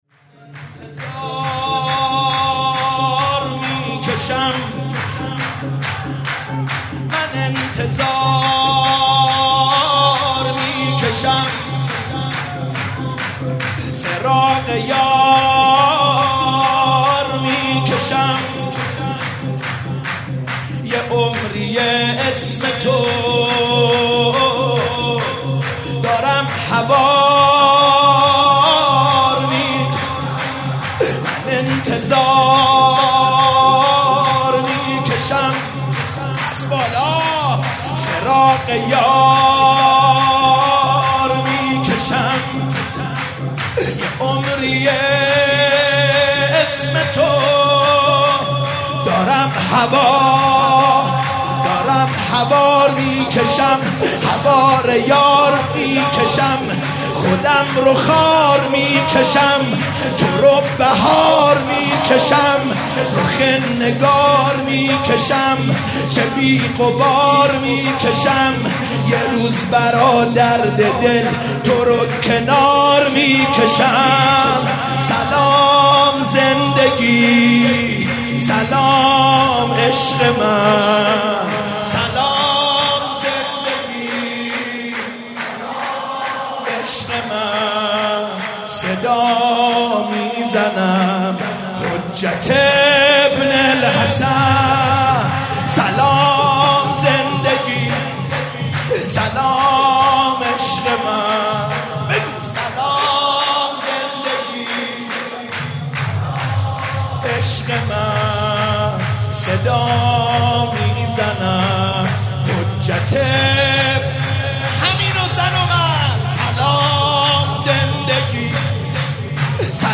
ولادت امام زمان عج97 - سرود